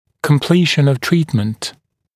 [kəm’pliːʃn əv ‘triːtmənt][кэм’пли:шн ов ‘три:тмэнт]завершение лечения